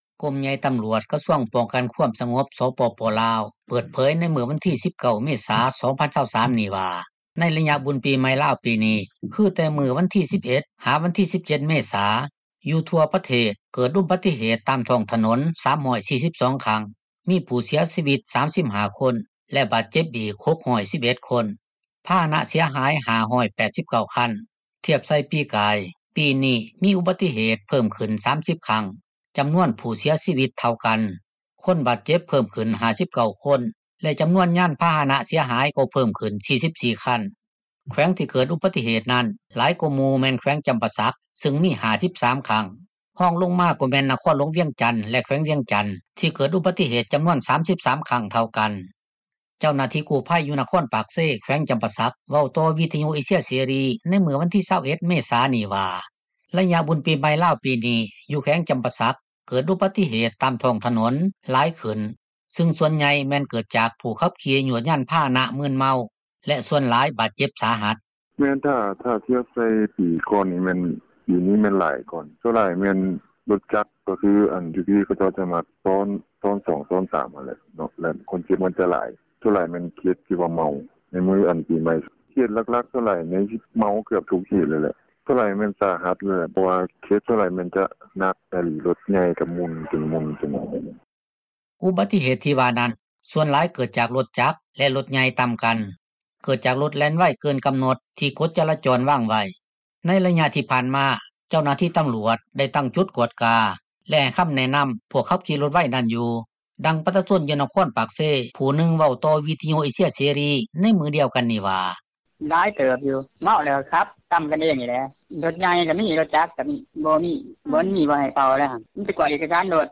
ເຈົ້າໜ້າທີ່ກູ້ພັຍ ຢູ່ນະຄອນປາກເຊ ແຂວງຈໍາປາສັກ ເວົ້າຕໍ່ວິທຍຸເອເຊັຽເສຣີ ໃນມື້ວັນທີ 21 ເມສານີ້ວ່າ ໄລຍະບຸນປີໃໝ່ລາວປີນີ້ ຢູ່ແຂວງຈໍາປາສັກ ເກີດອຸບັດຕິເຫດຕາມທ້ອງຖນົນຫລາຍຂຶ້ນ ຊຶ່ງສ່ວນໃຫຍ່ແມ່ນເກີດຈາກຜູ້ຂັບຂີ່ ຍວດຍານພາຫະນະ ໃນຂະນະທີມືນເມົາ ແລະສ່ວນຫລາຍບາດເຈັບສາຫັດ.
ດັ່ງປະຊາຊົນຢູ່ນະຄອນປາກເຊ ຜູ້ນຶ່ງເວົ້າຕໍ່ວິທຍຸເອເຊັຽເສຣີໃນມື້ດຽວກັນນີ້ວ່າ: